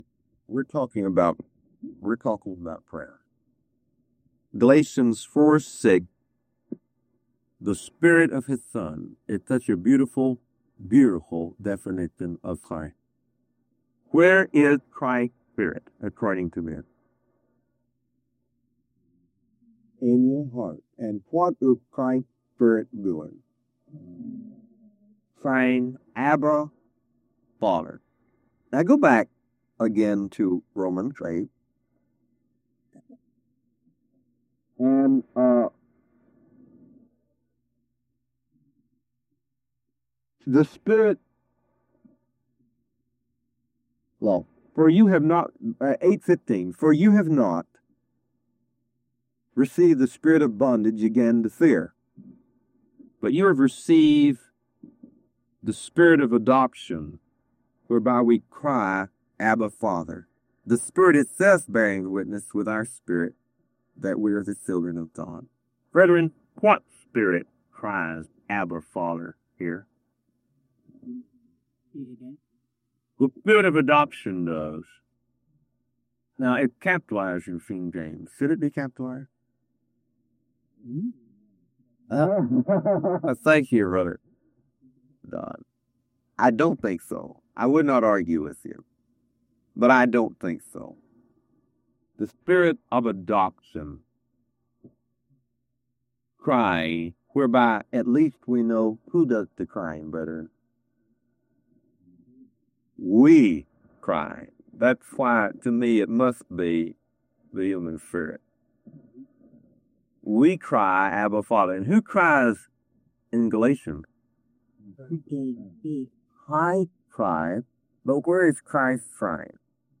Discover how prayer flows from Christ within—not requests or formulas. A deep biblical teaching on prayer in the Spirit.